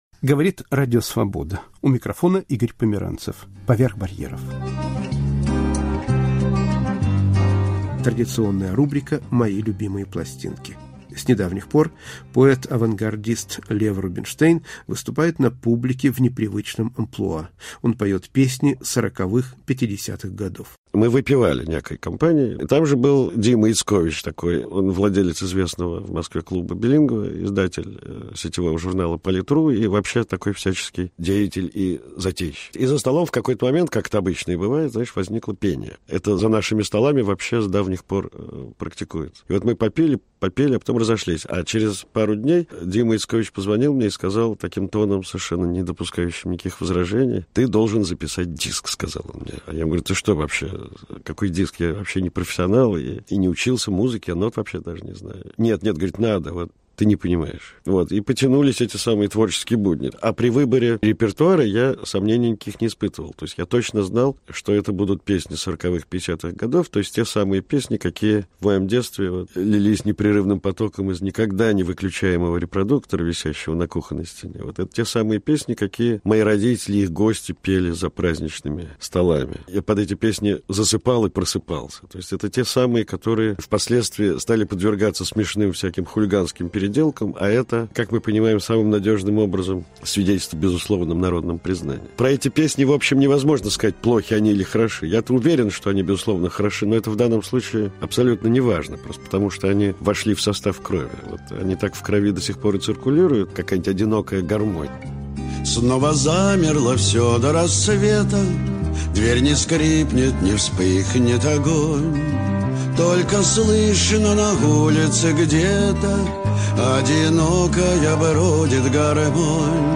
Поэт в роли певца